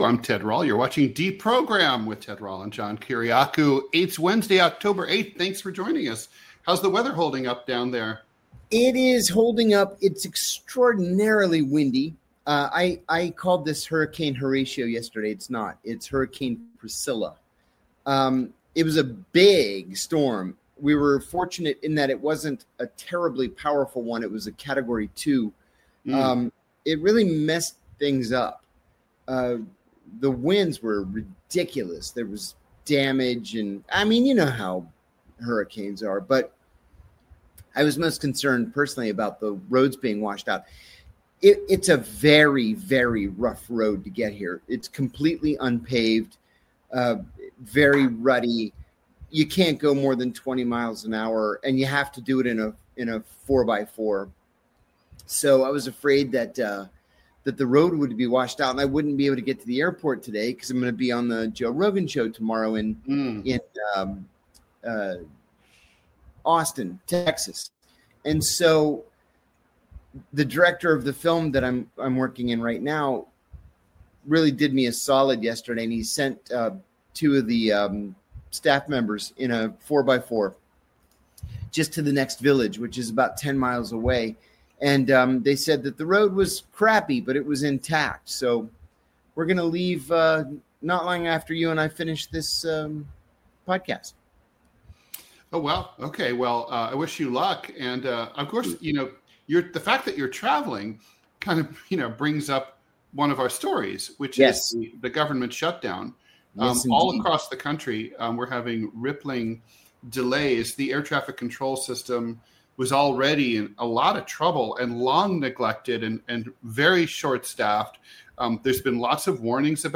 Political cartoonist Ted Rall and CIA whistleblower John Kiriakou look at the Trump administration's plan to mint a $1 coin with the president's image, his messaging to furloughed government workers that they may forfeit back pay, Colorado's conversion therapy ban, and CIA Deputy Director Michael Ellis demotion of the acting general counsel—a career lawyer—and assuming the role himself.